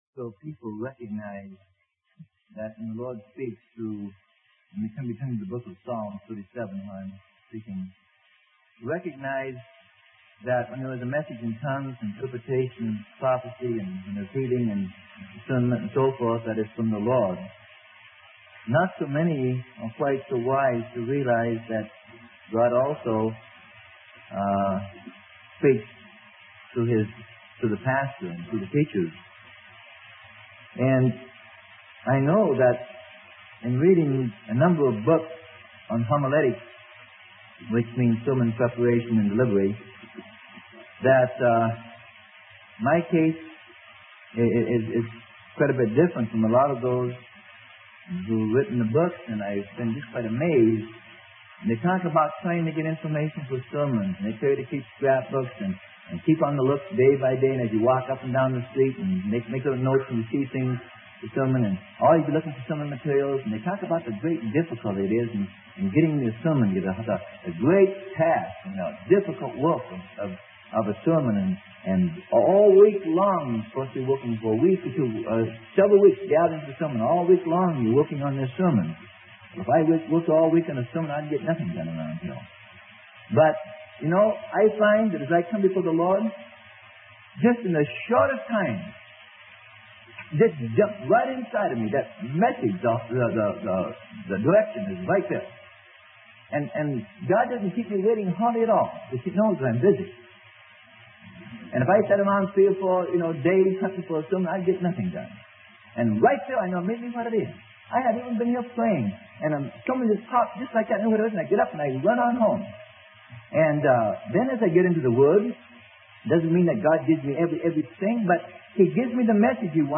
Sermon: Not on a String: Subtitles of Calvinism - Freely Given Online Library